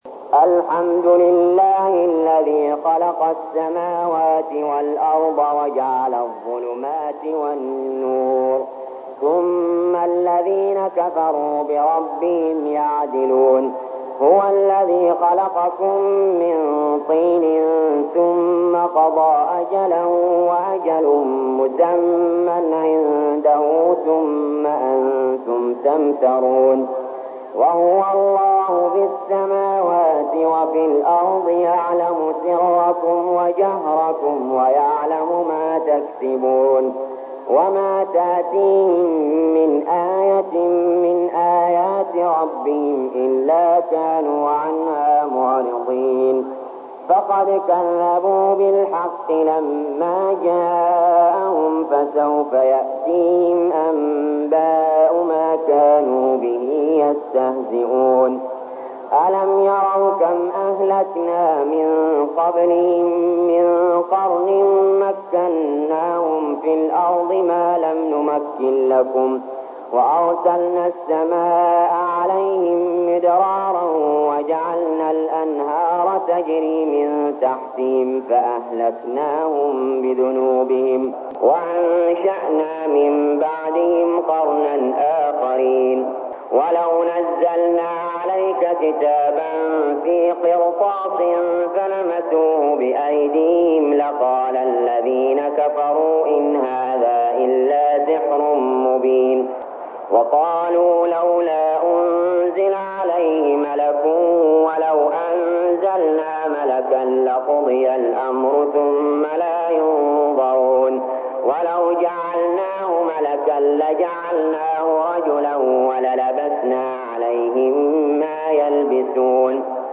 المكان: المسجد الحرام الشيخ: علي جابر رحمه الله علي جابر رحمه الله الأنعام